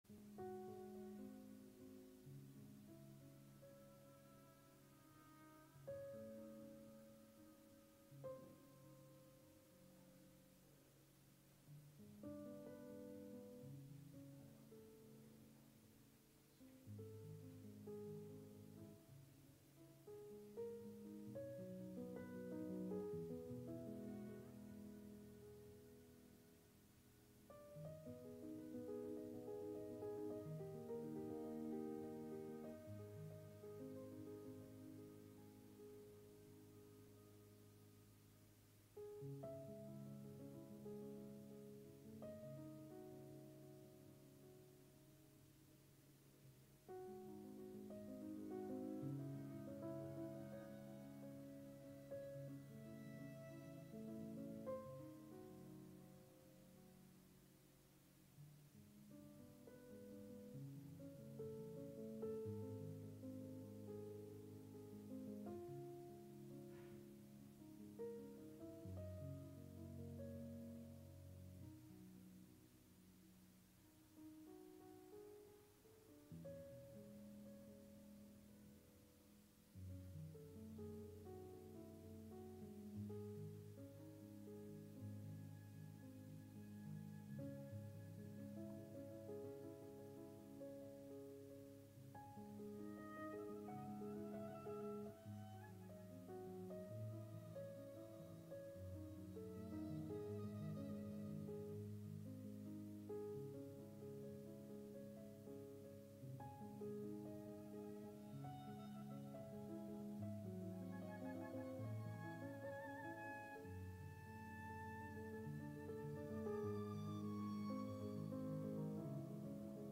The Service of Shadows is a quiet, reflective service following the events that led up to the crucifixion and death of Jesus Christ.